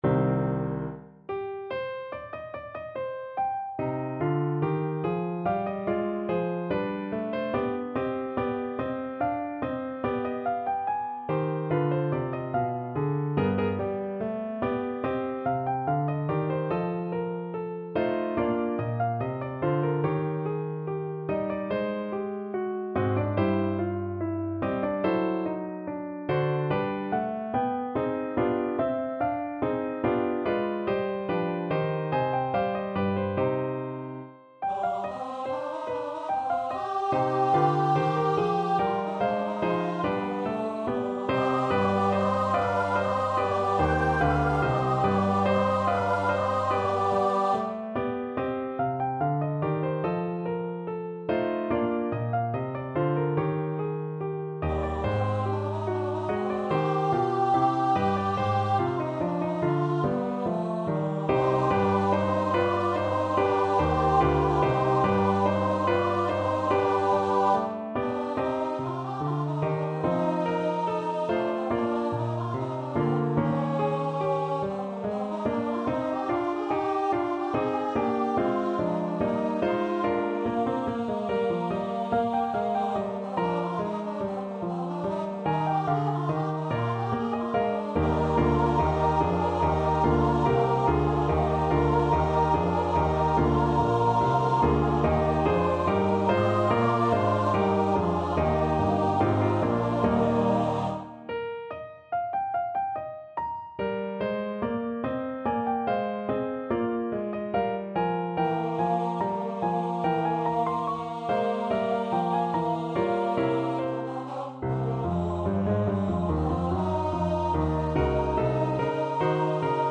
Free Sheet music for Choir
Choir  (View more Intermediate Choir Music)
Classical (View more Classical Choir Music)